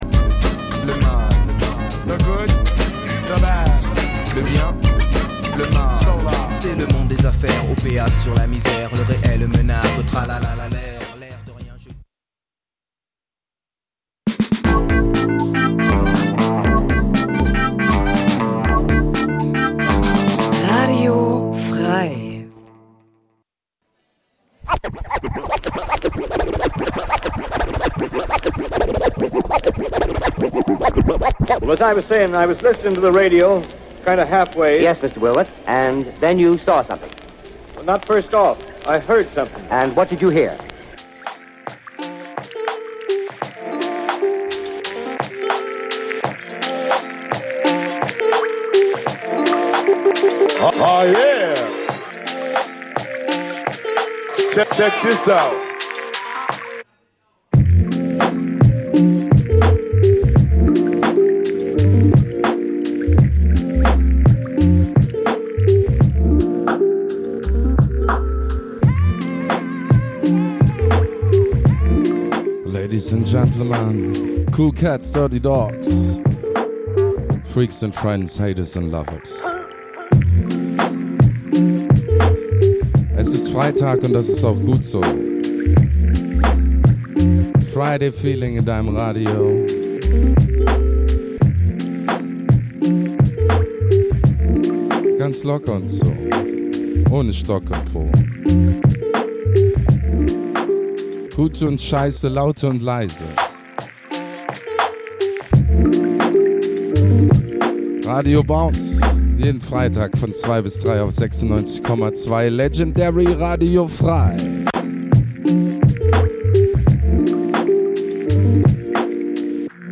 Wenn der Groove Deine Seele streichelt, die Drums ungezogen ins Ohr fl�stern, w�hrend Loops und Schleifen in entspannten Kreisen schweifen und dabei mehr gelacht als gedacht wird, sind Deine Ohren bei Radio Bounce - Gurgelnd knusprige Wellen aus der Hammerschmiede f�r leidenschafltiche Sch�ngeister, pudelnackt!